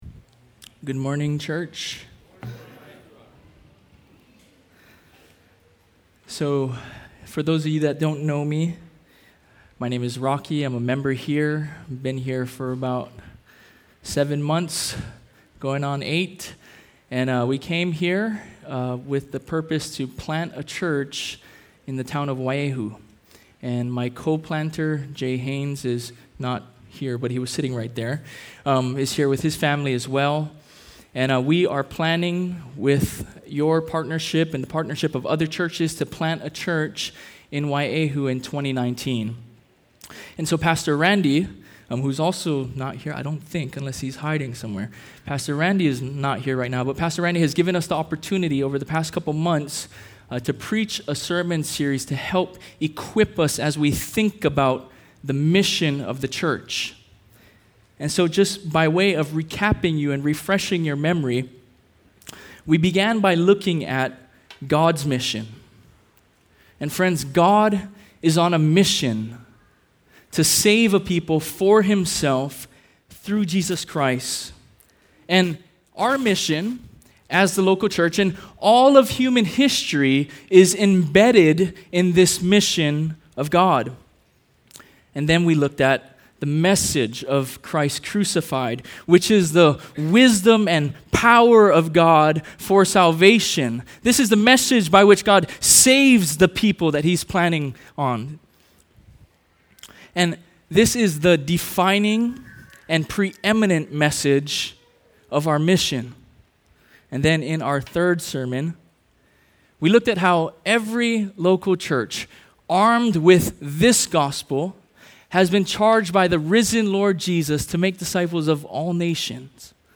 Kahului Baptist Church Sermons | Kahului Baptist Church